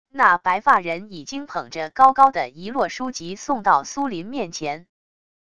那白发人已经捧着高高的一摞书籍送到苏林面前wav音频生成系统WAV Audio Player